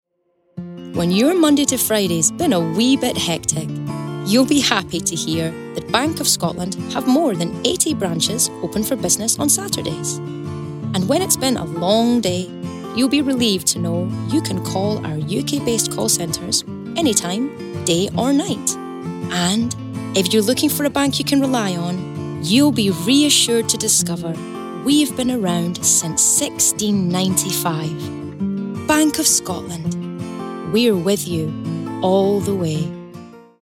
Scottish
Female
Friendly
Warm